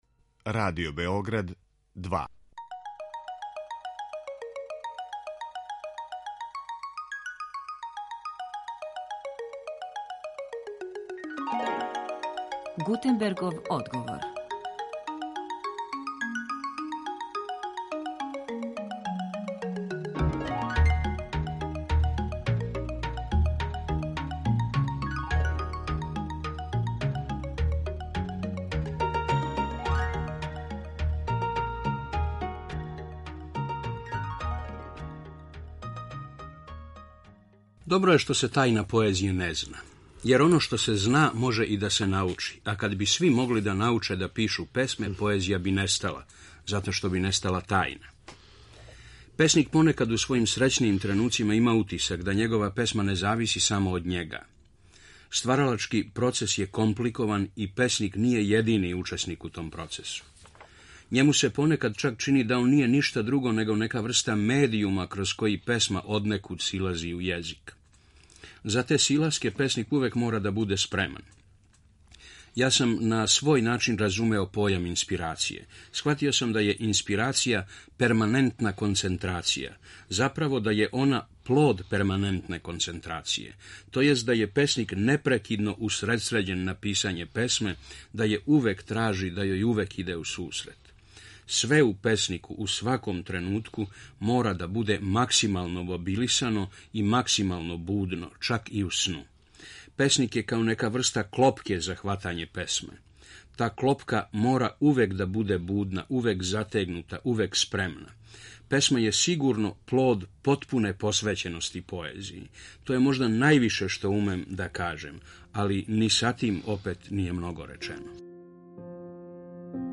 Са посебним задовољством присећамо се мисли Љубомира Симовића о поетском чину, језику, детињству и у прилици смо да слушамо избор из његове поезије.